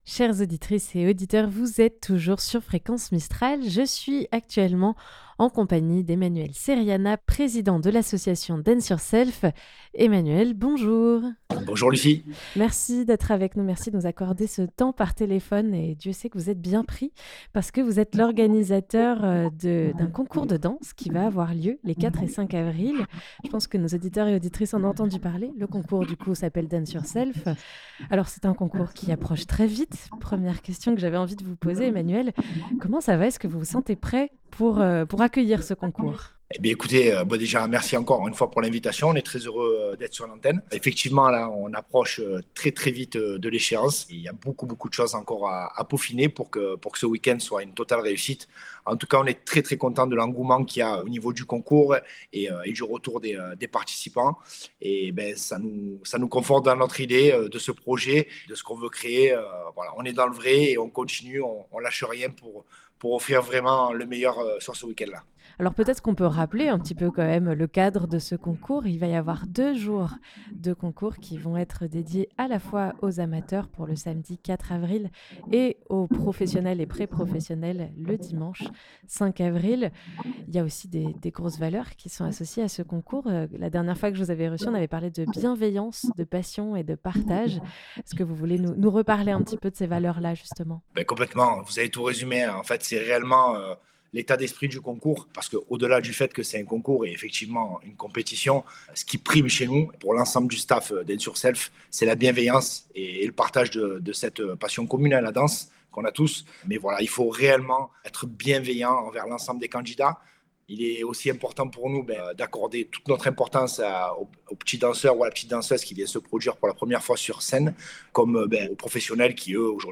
Avec l'arrivée imminente des dates clé, il nous a de nouveau répondu par téléphone - l'occasion d'annoncer beaucoup de bonnes nouvelles ! 260317 - Dance Yourself Reannonce.mp3 (31.47 Mo)